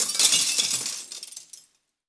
glass_smashable_debris_fall_04.wav